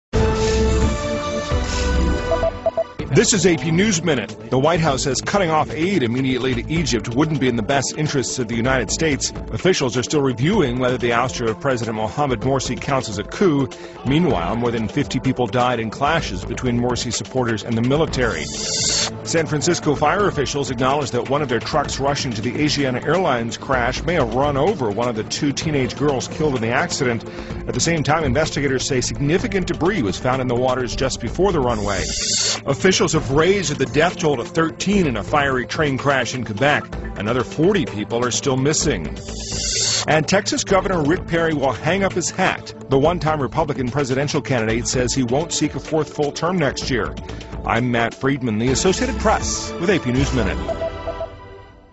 在线英语听力室美联社新闻一分钟 AP 2013-07-12的听力文件下载,美联社新闻一分钟2013,英语听力,英语新闻,英语MP3 由美联社编辑的一分钟国际电视新闻，报道每天发生的重大国际事件。电视新闻片长一分钟，一般包括五个小段，简明扼要，语言规范，便于大家快速了解世界大事。